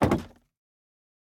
Minecraft Version Minecraft Version latest Latest Release | Latest Snapshot latest / assets / minecraft / sounds / block / bamboo_wood_door / toggle3.ogg Compare With Compare With Latest Release | Latest Snapshot
toggle3.ogg